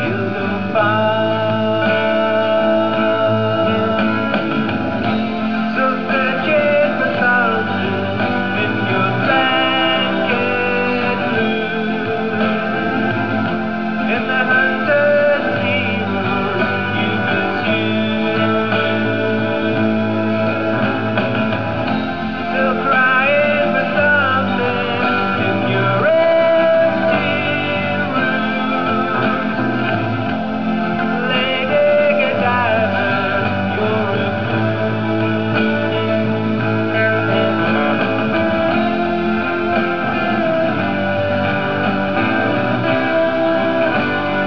The Cowshed is my recording studio, where I pursue my passion for songwriting and try to make what I've written sound half-decent.
Recorded 8 August 1996 in The Cowshed.